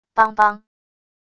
梆梆――wav音频